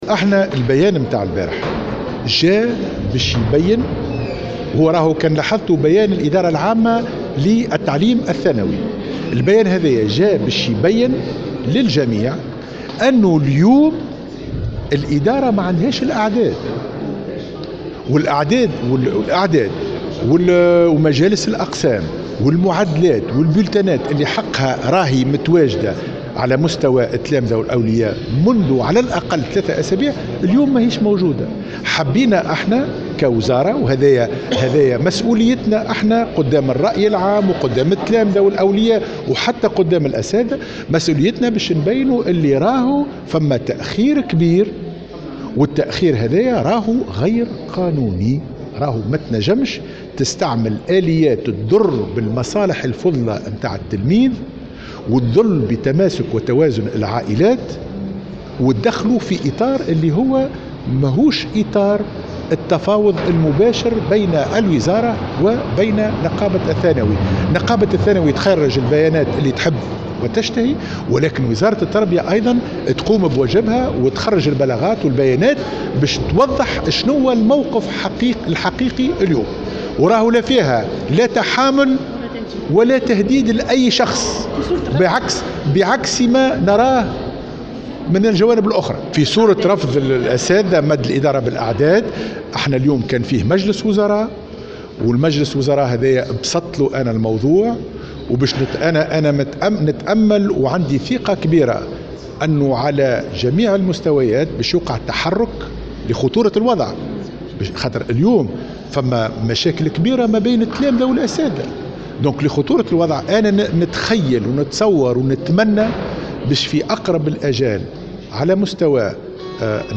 وزير التربية